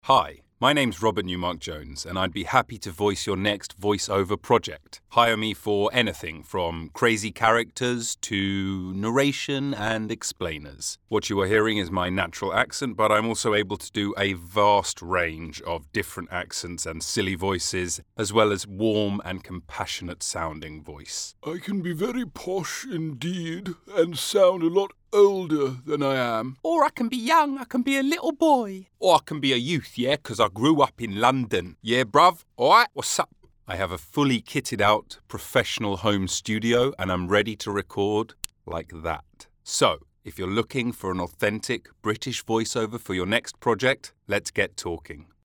Commercial
Male
British English (Native)
Natural, Warm, Engaging, Confident, Conversational, Cool, Corporate, Deep, Energetic, Friendly, Funny, Gravitas, Versatile, Witty
Microphone: Rode NT1A